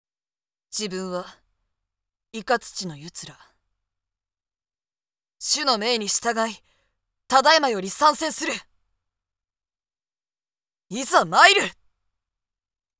【サンプルセリフ】